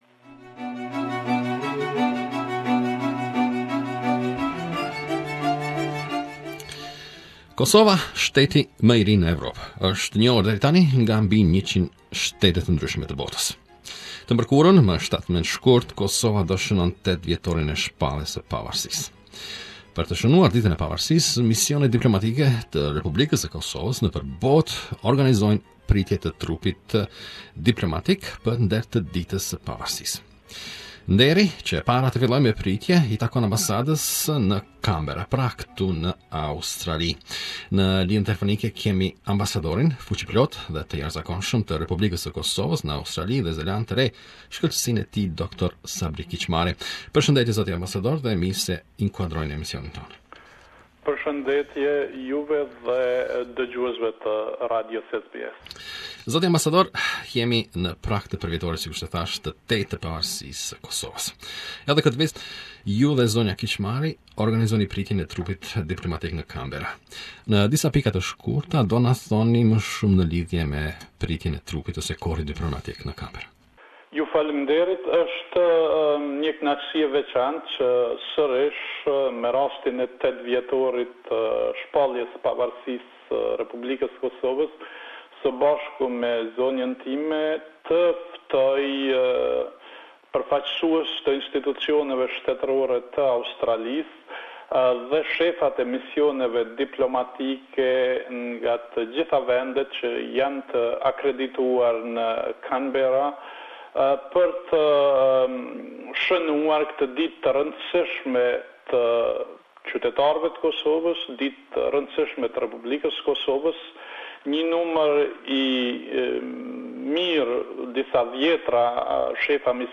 Interview with Ambassador of the Republic of Kosovo in Australia, Dr Sabri Kicmari